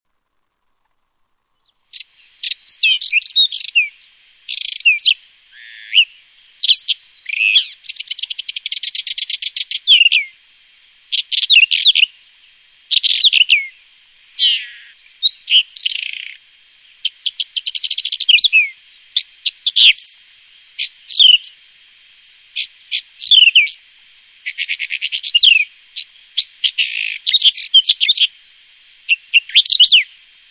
Tierstimme:
Gesang des Braunkehlchens, MP3, 240 KB
04 - Braunkehlchen.mp3